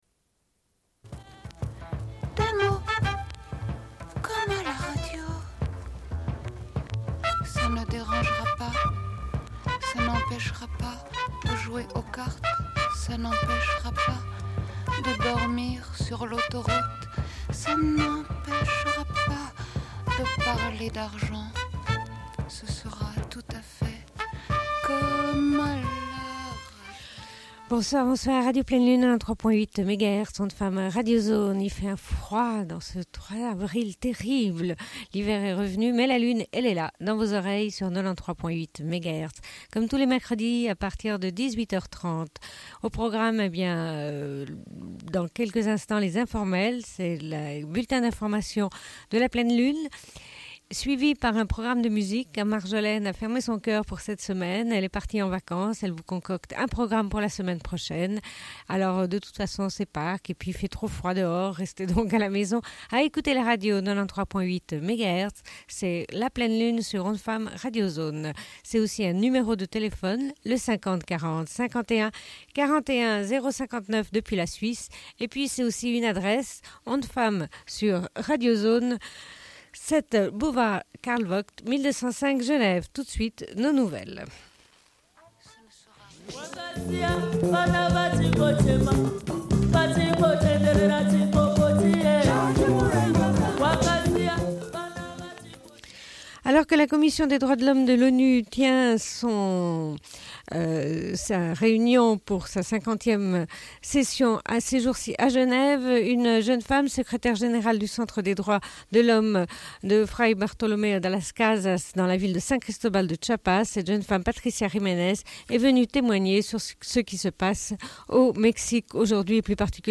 Bulletin d'information de Radio Pleine Lune du 03.04.1996 - Archives contestataires
Une cassette audio, face B